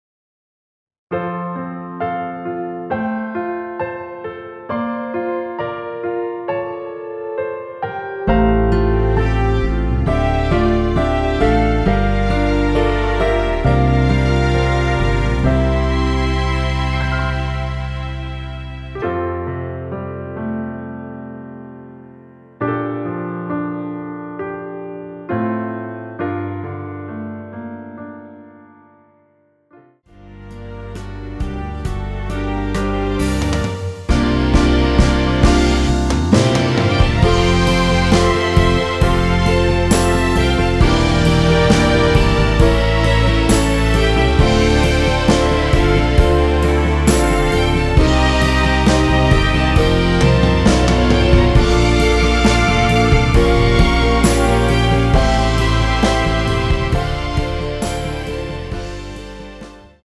대부분의 여성분이 부르실수 있는 키로 제작 하였습니다.
원키에서(+4)올린 MR입니다.
앞부분30초, 뒷부분30초씩 편집해서 올려 드리고 있습니다.
중간에 음이 끈어지고 다시 나오는 이유는